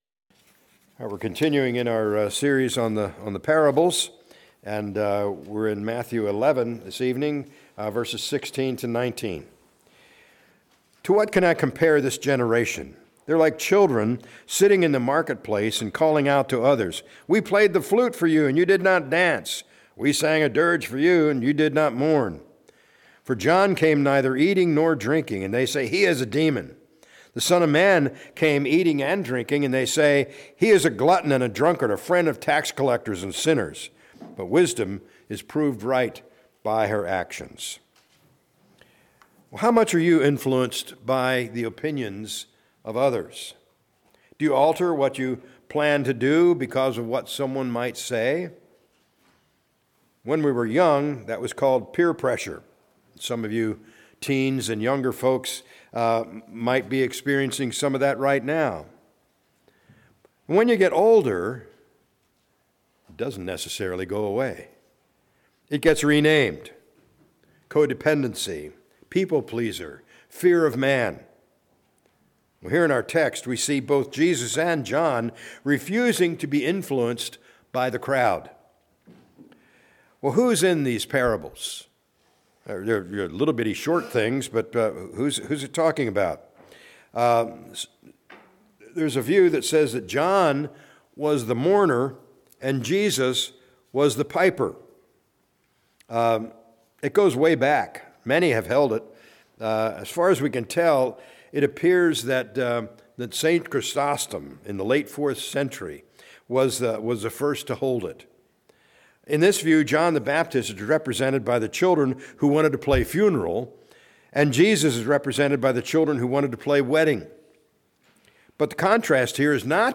A message from the series "Parables of Jesus."